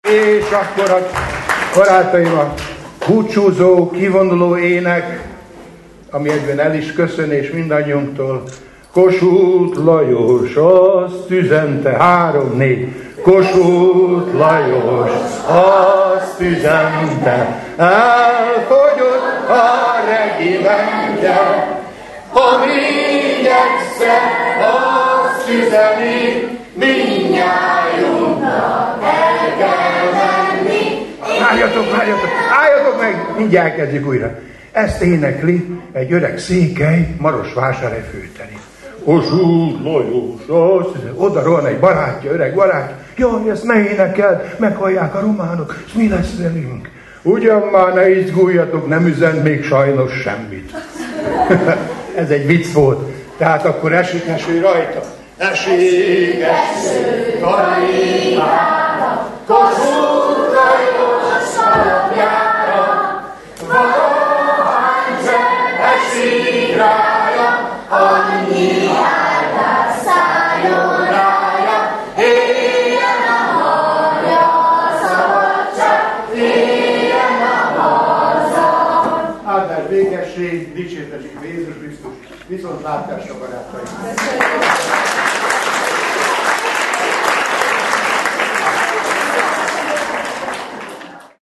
A záróének, nem meglepetés, a Kossuth Lajos azt üzente című népdal volt, amelyet, ugye, nem meglepetés, előadó és közönség együtt énekelt.